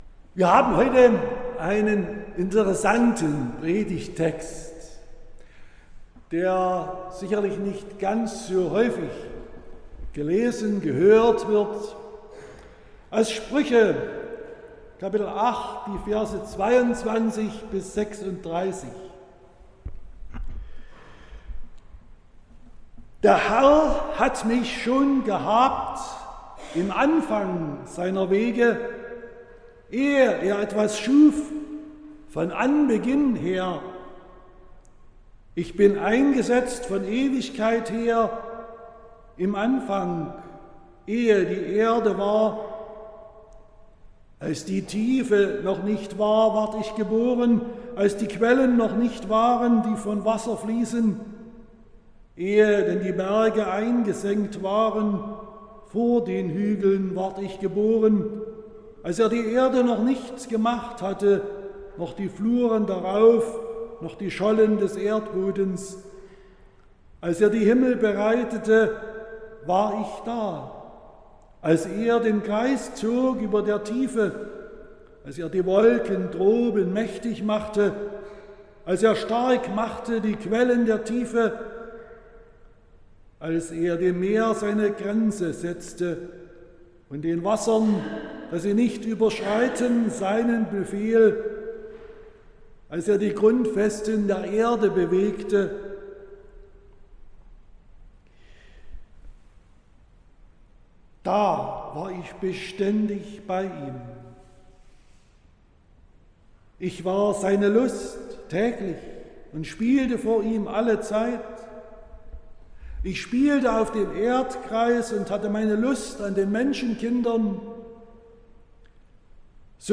11.05.2025 – Gottesdienst
Predigt (Audio): 2025-05-11_Weisheit_und_Schoepfung.mp3 (30,8 MB)